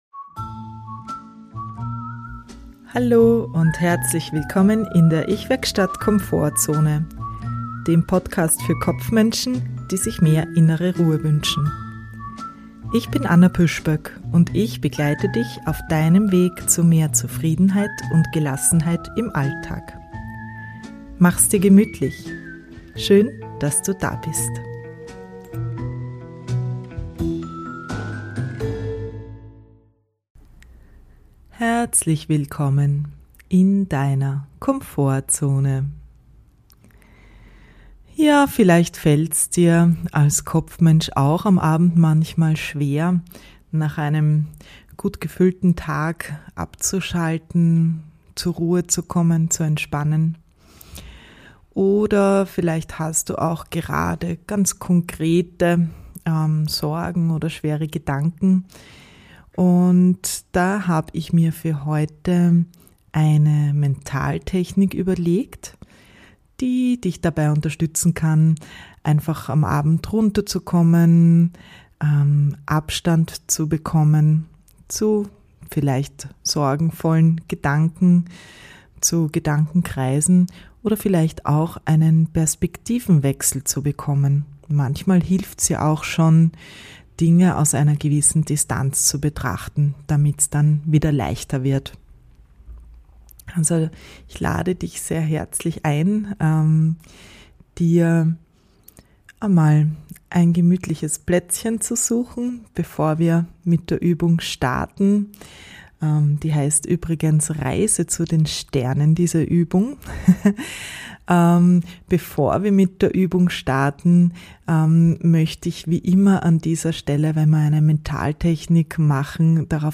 Eine Mentalübung, um zur Ruhe zu kommen und Abstand zum Alltag zu bekommen.